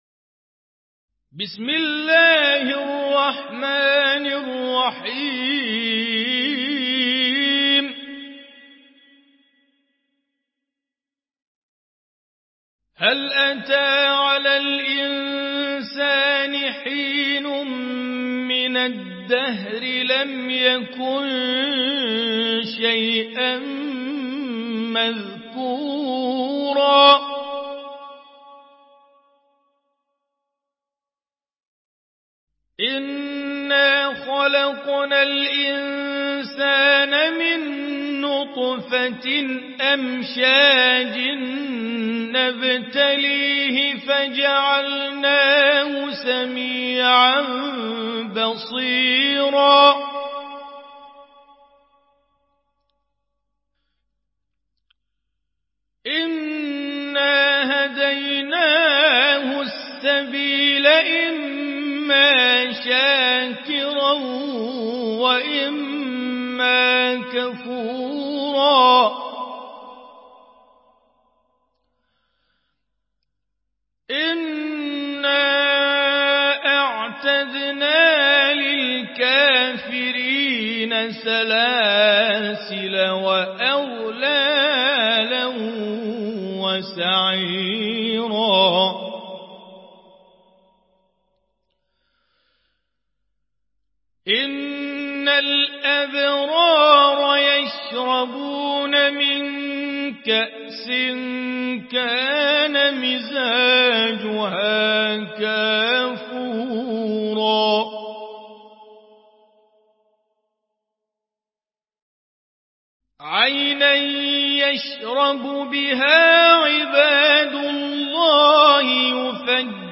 Murattal
তেলাওয়াত